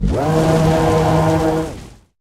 Cri de Flâmigator dans Pokémon HOME.